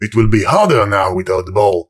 brickmove04.ogg